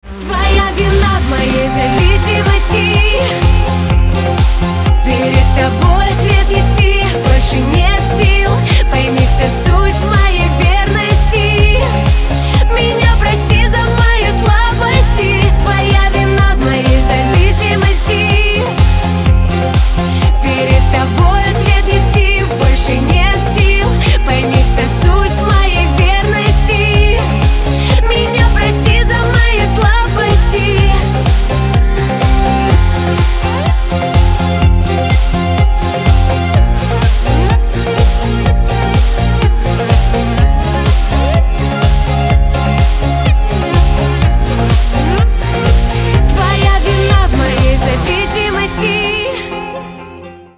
• Качество: 256, Stereo
попса